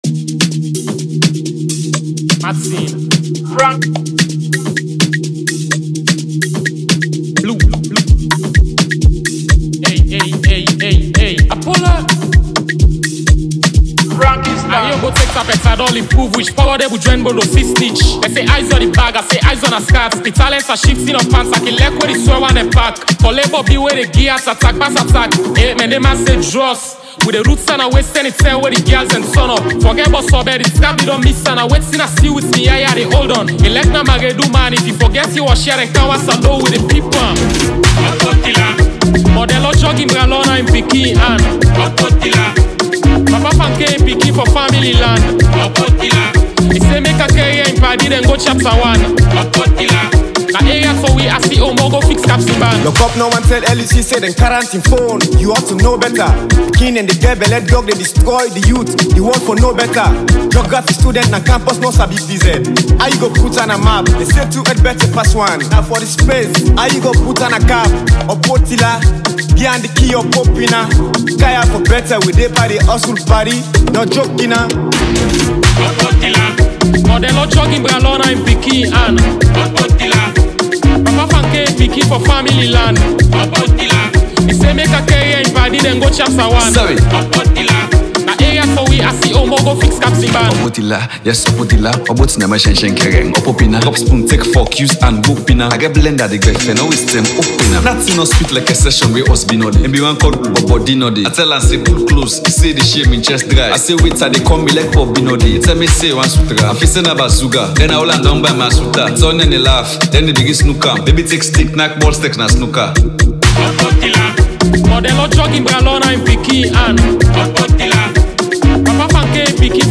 street anthem
rap song with street and club vibes